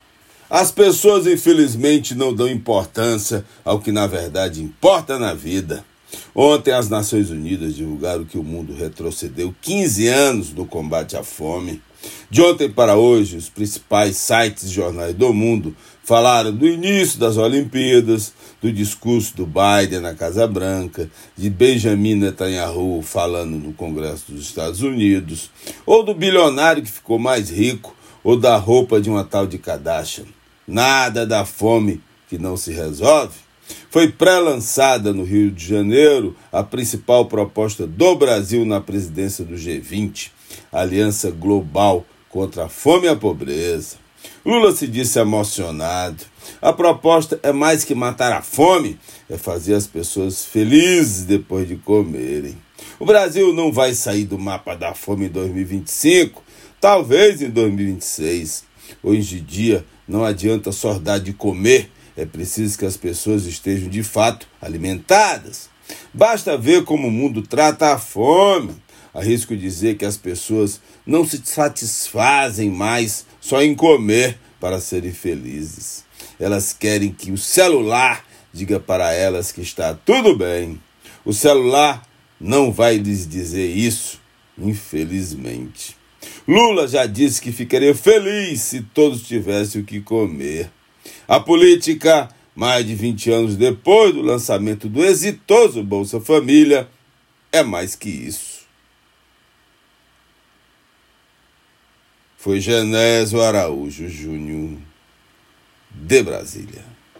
COMENTÁRIO DIRETO DE BRASIL